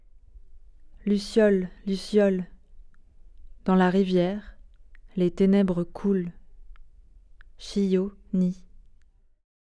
La fontaine décorative offre ses petits bruits de clapotis en fond sonore.
son-1-haiku-lucioles.mp3